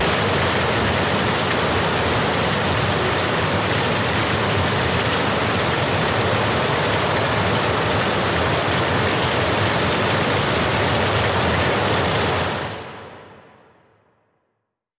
For example, we created this 15-second track by selecting the moods beautiful and peaceful.